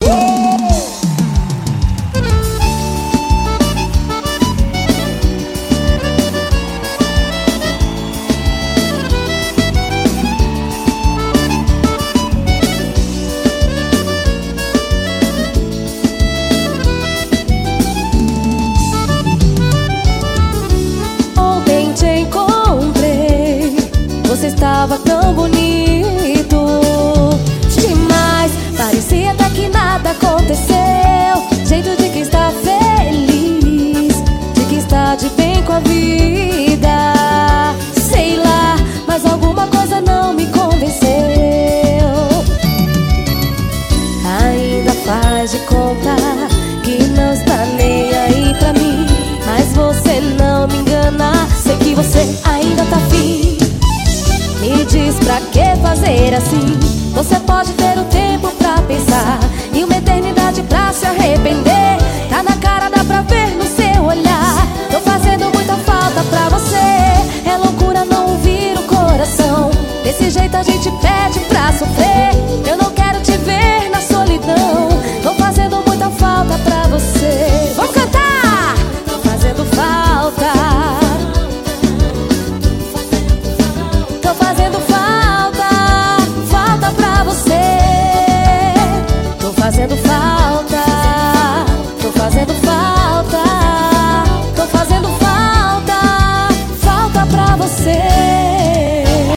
SHOW AO VIVO.